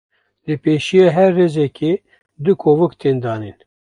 Lees meer Betekenis (Engels) (feminine) funnel (feminine) anus Vertalings funnel Trichter argoda kıç huni Uitgespreek as (IPA) /koːˈvɪk/ Boekmerk dit Verbeter jou uitspraak Notes Sign in to write sticky notes